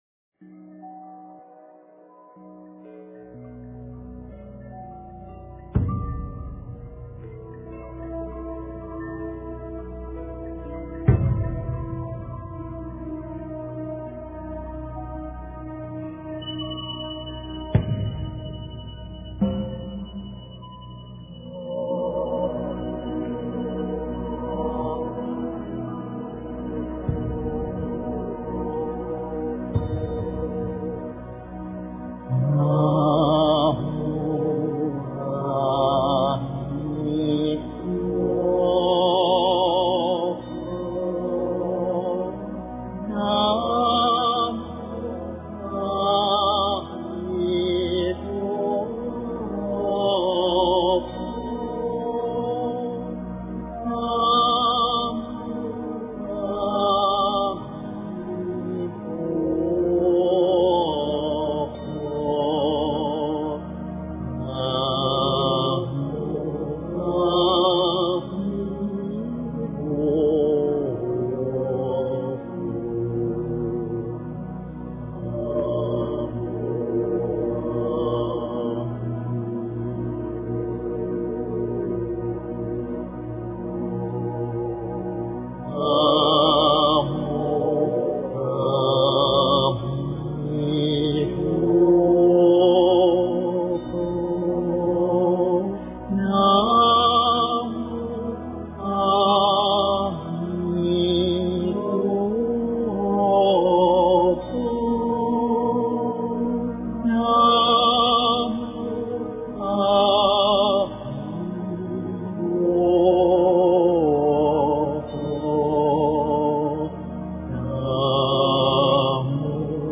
经忏
佛音 经忏 佛教音乐 返回列表 上一篇： 地藏赞--圆光佛学院众法师 下一篇： 回向偈--圆光佛学院众法师 相关文章 释迦牟尼佛心咒--琼英卓玛 释迦牟尼佛心咒--琼英卓玛...